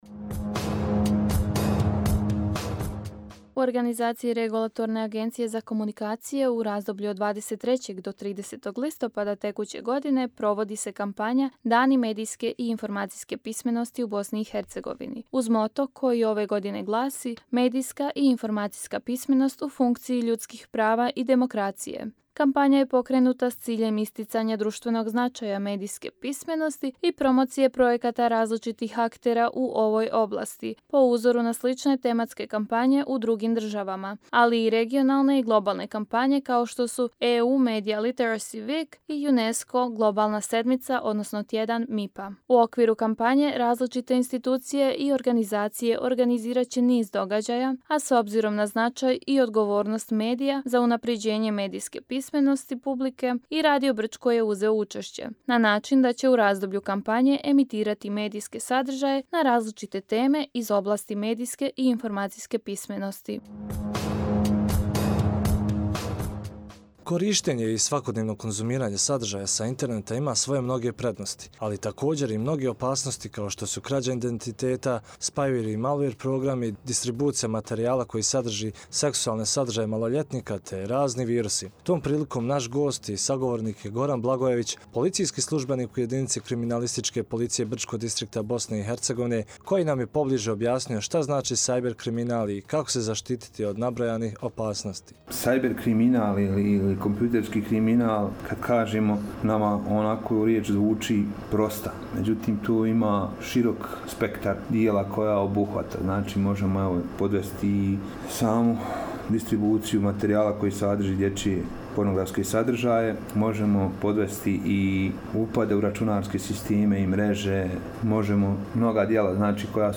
Emisija o medijskoj pismenosti sa policijskim službenikom